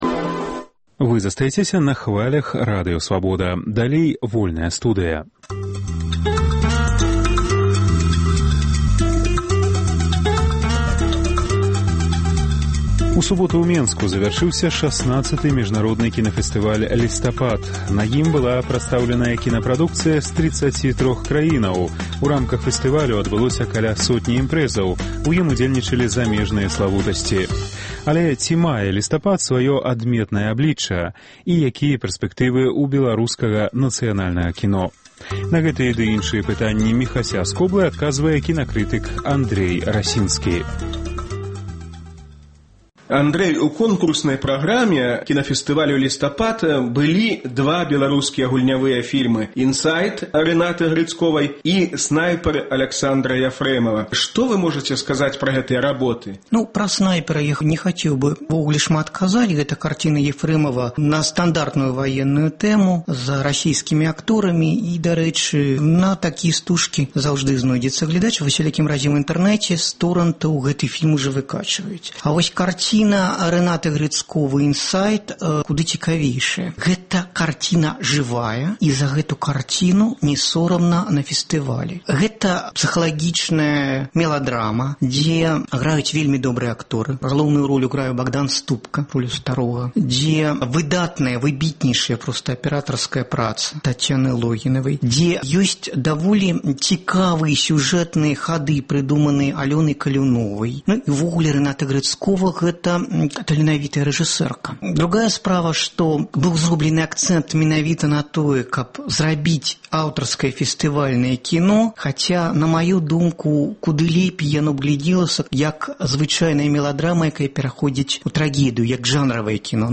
Гутаркі без цэнзуры зь дзеячамі культуры й навукі. Менскі кінафэстываль “Лістапад” за шаснаццаць гадоў так і не набыў сваё ўласнае аблічча.